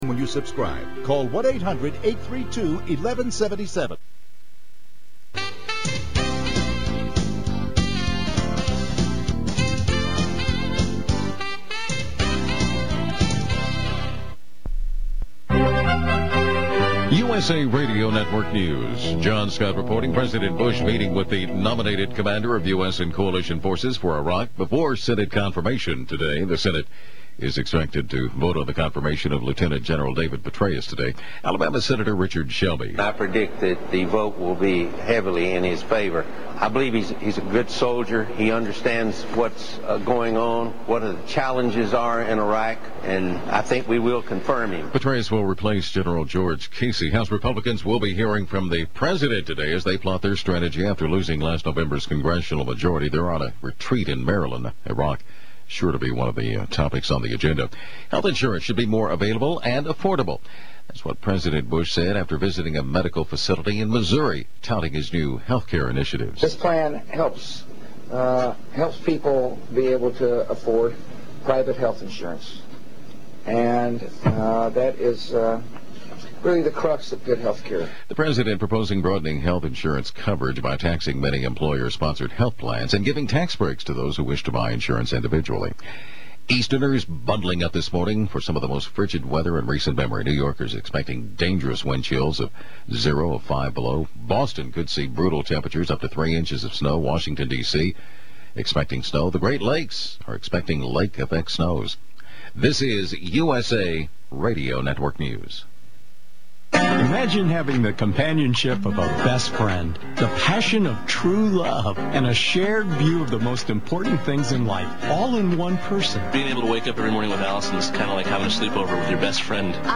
/UploadedFiles/KLIF%20570%20AM%20%20Dallas,%20TX%20Jan%2023.mp3